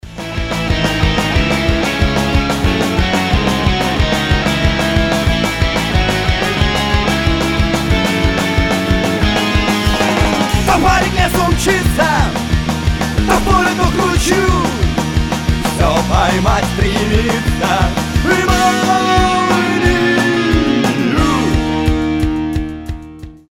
• Качество: 320, Stereo
панк-рок